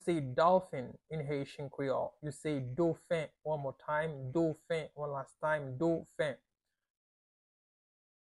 Listen to and watch “Dofen” audio pronunciation in Haitian Creole by a native Haitian  in the video below:
Dolphin-in-Haitian-Creole-Dofen-pronunciation-by-a-Haitian-teacher.mp3